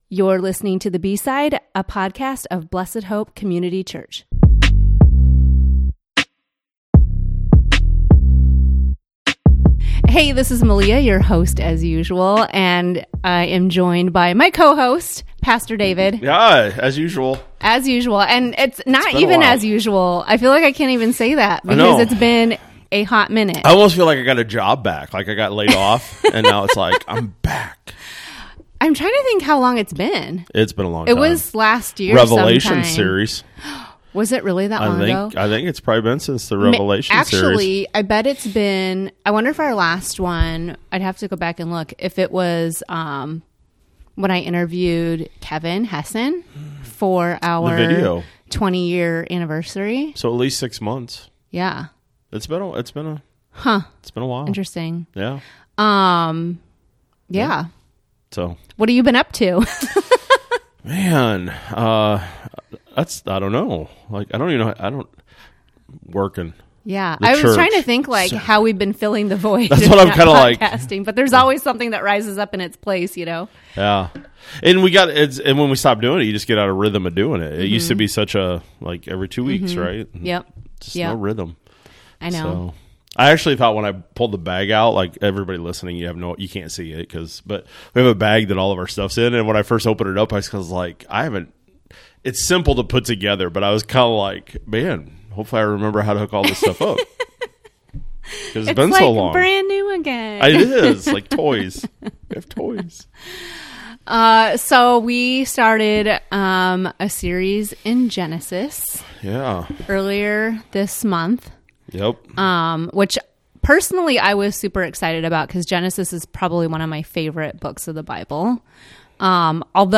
After a six-month break, The B-Side returns with a conversation on evolution—what it means, what the evidence shows, and why interpretation matters. We discuss rock layers, transitional fossils, dating methods, and how our approach to science reveals deeper assumptions about Scripture and faith. We end by revisiting a crucial question: When did sin enter the world?